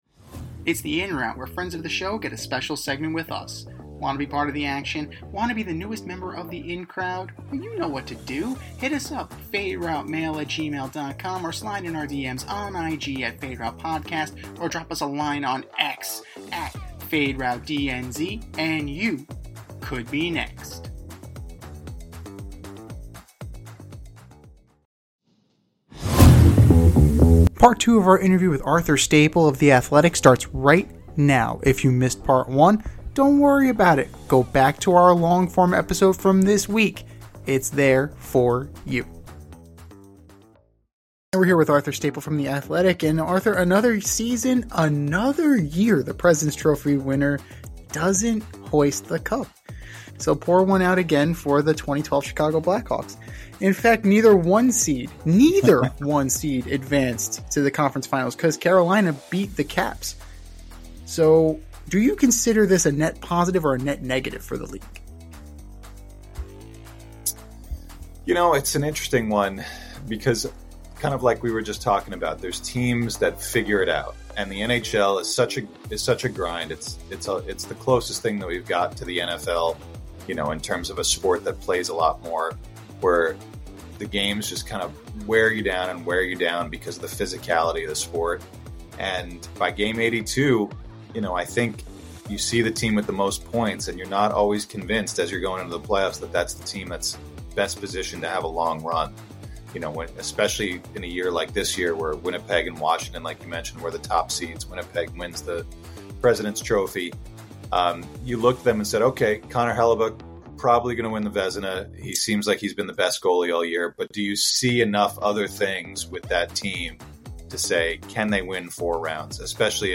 In part two of our interview